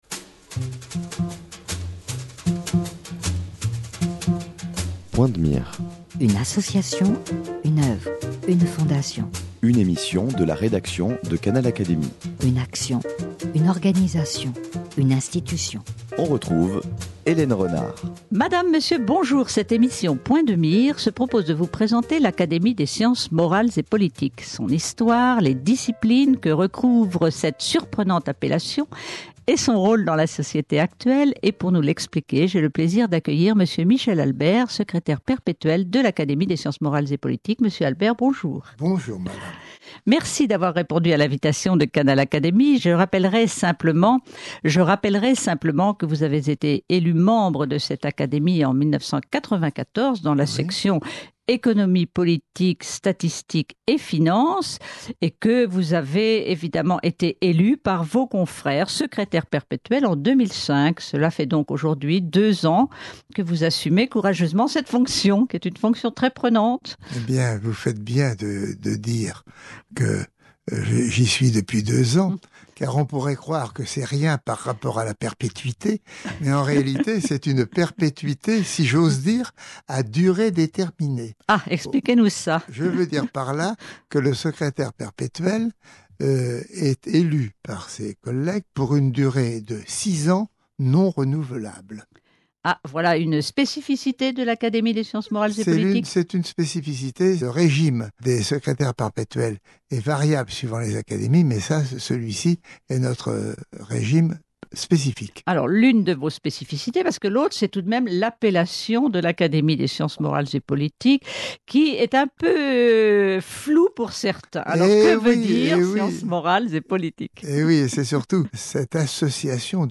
Explications par Michel Albert, élu par ses confrères Secrétaire Perpétuel en 2005.